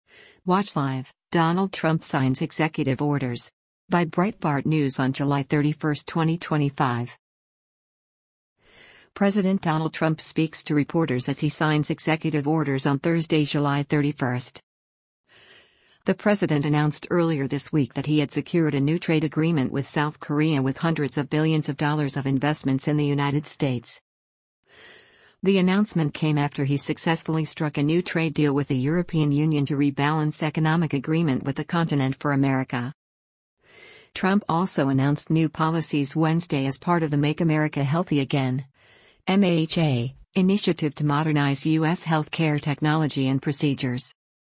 President Donald Trump speaks to reporters as he signs executive orders on Thursday, July 31.